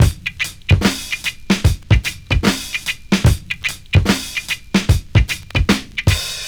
• 74 Bpm 2000s Breakbeat Sample D# Key.wav
Free drum beat - kick tuned to the D# note. Loudest frequency: 1430Hz